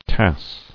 [tasse]